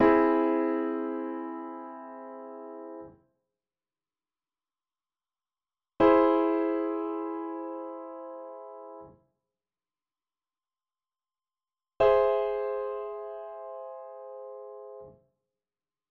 Augmented triads - all inversions sound the same.
3. Augmented triads – all intervals sound the same